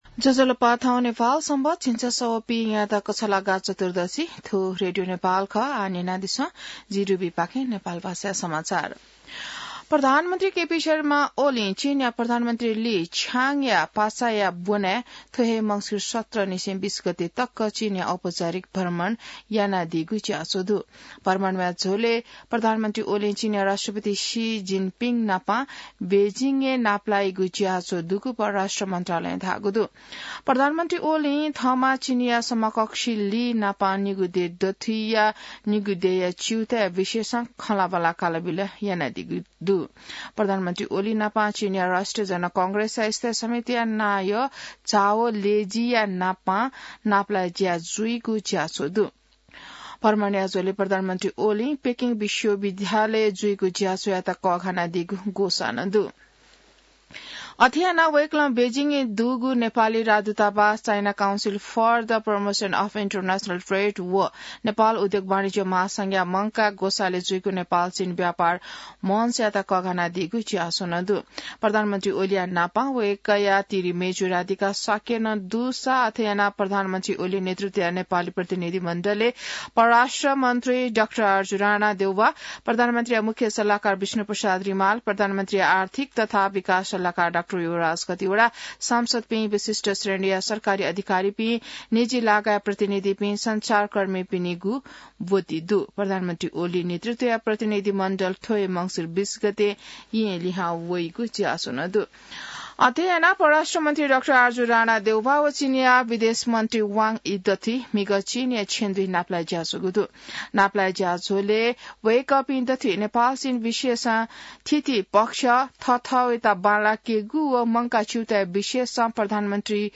नेपाल भाषामा समाचार : १६ मंसिर , २०८१